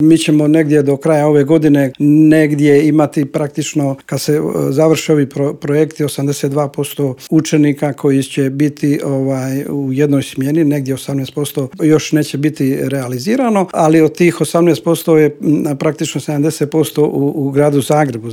Teme su to o kojima smo u Intervjuu Media servisa razgovarali s ravnateljem Uprave za potporu i unaprjeđenje sustava odgoja i obrazovanja u Ministarstvu obrazovanja Momirom Karinom.